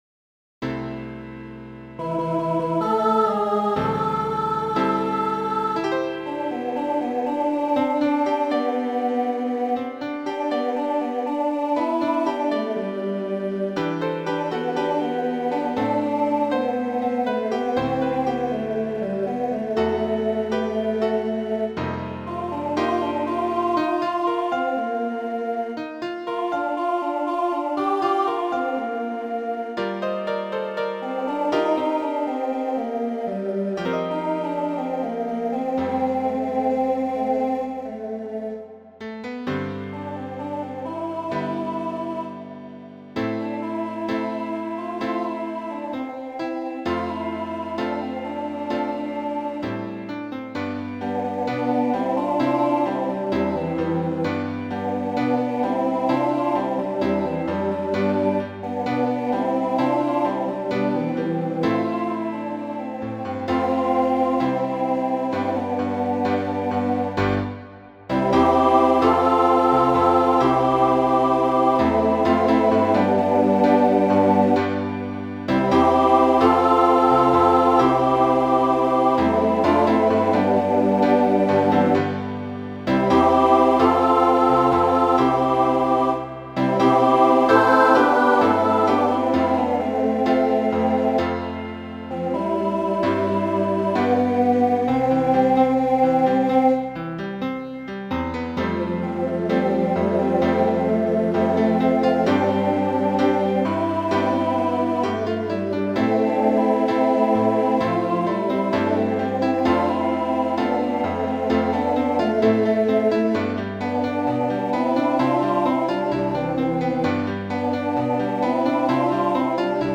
Voicing SATB Instrumental combo Genre Country
Function Ballad